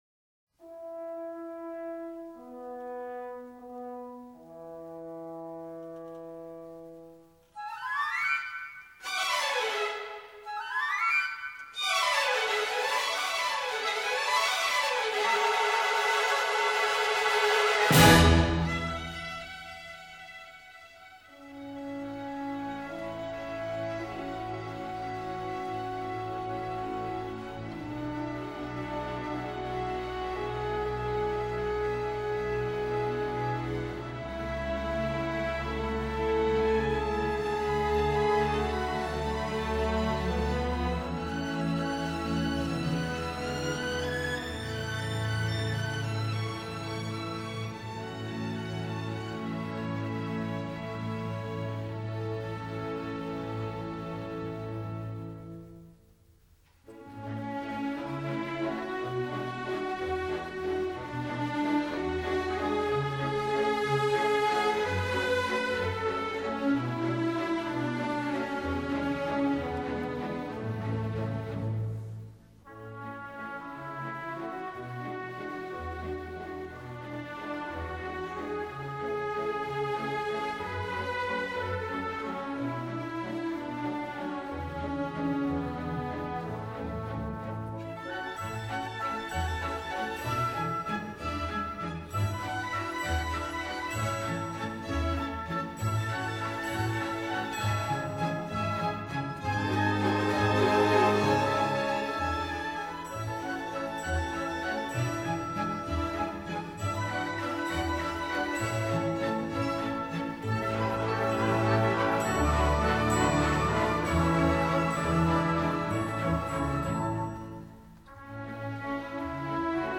乐曲采用维也纳圆舞曲形式，由序奏、四个小圆舞曲及结尾组成。
序奏中圆号奏出的徐缓的旋律，使人联想到冬天的景色，转为圆舞曲速度之后，呈现出本曲的主要主题——第一小圆舞曲的第一主题。
接下来的第一小圆舞曲第二主题运用八分音符并强调其节奏，使乐曲充满了轻松活泼的情绪。
从容的第三小圆舞曲第一主题主要由弦乐奏出，具有与第一小圆舞曲同样舒展平稳、流畅悠闲的特点。
欢快的华彩段落之后，音乐进入结尾部分，再现开头的第一圆舞曲，并在热烈的气氛中结束全曲。